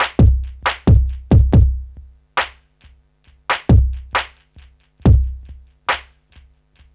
C E.BEAT 1-L.wav